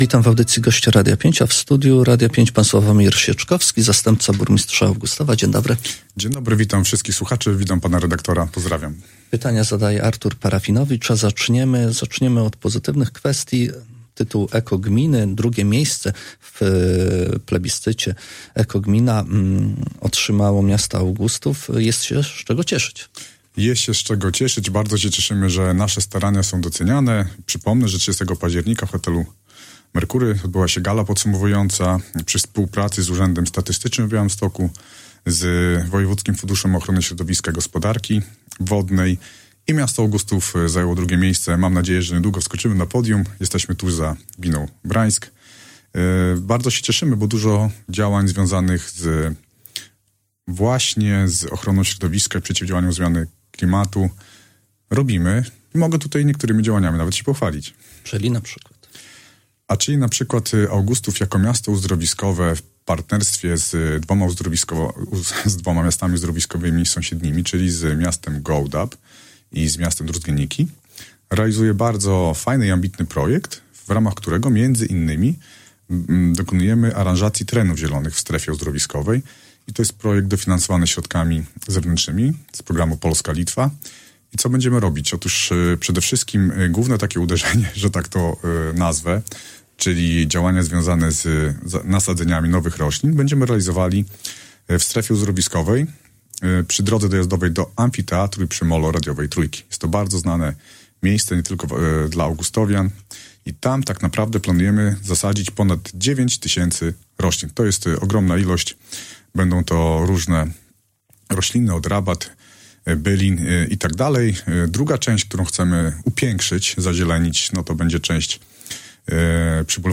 O miejskich inwestycjach, 2. miejscu w konkursie EKO Gmina oraz możliwości odpracowania długów komunalnych mówił w Radiu 5 Sławomir Sieczkowski, zastępca burmistrza Augustowa.